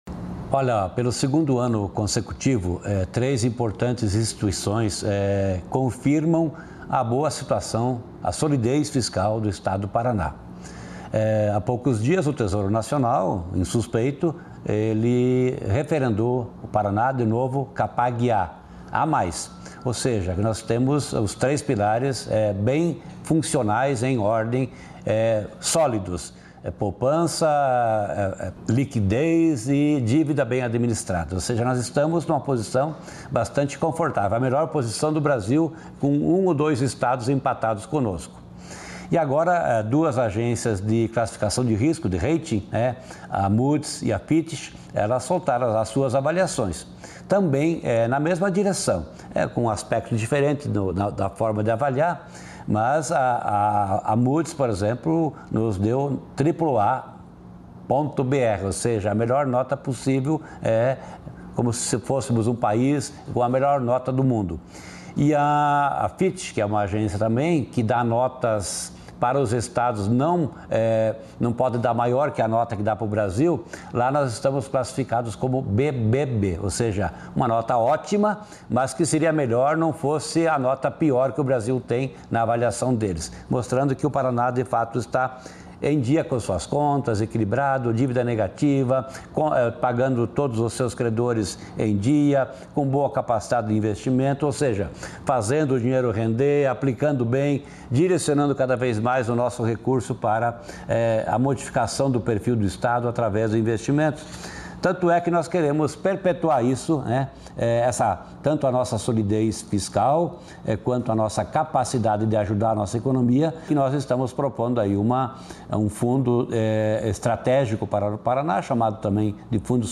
Sonora do secretário da Fazenda, Norberto Ortigara, sobre nota máxima nos principais indicadores fiscais nacionais e internacionais